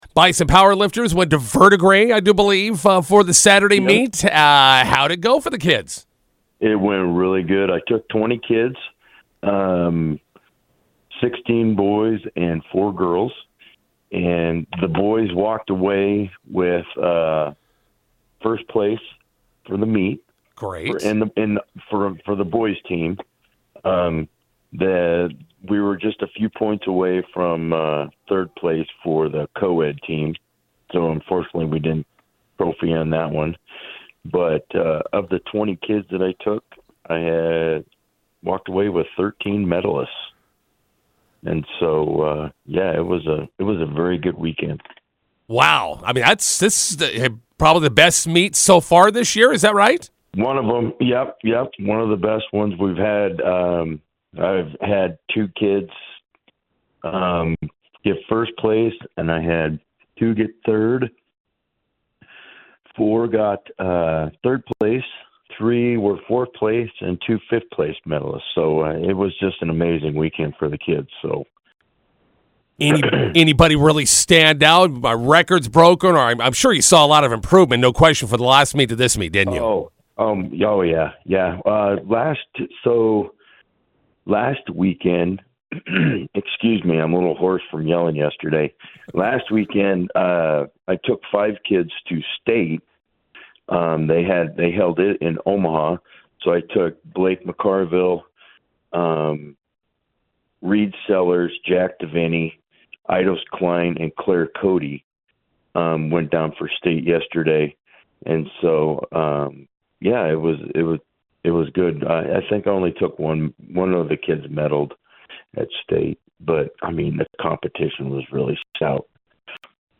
INTERVIEW: Bison powerlifters compete in state competition.